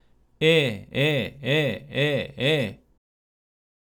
まずは、グーの声で「え」の音、もしくは「あ」の音を区切って5回発声します。
※見本音声(「え」の音)
h01_vibrato_G_e5.mp3